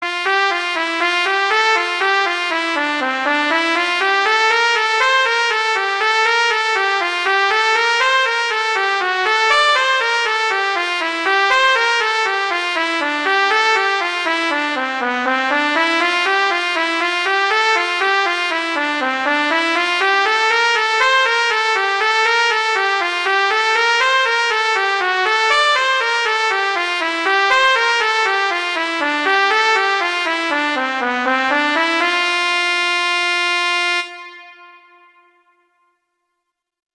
arrangements for trumpet solo
classical, children